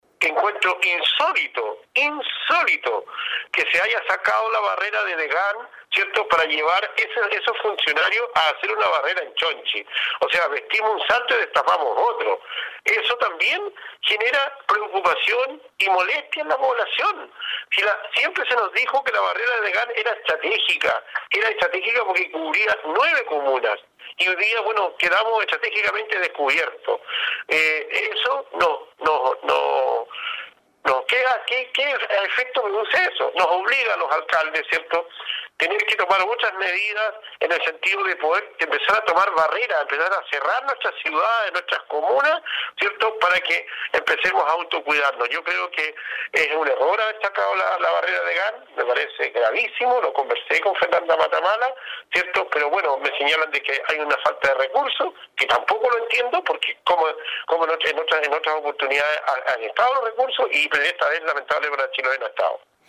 Con ello ya suman 25 confirmados, según lo dio a conocer el alcalde de la comuna, Juan Hijerra, quien se mostró muy inquieto por el gran número de contactos estrechos que se originan con estos positivos, como lo aseguró en entrevista con radio Estrella del Mar.